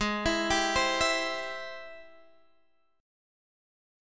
G#+M7 chord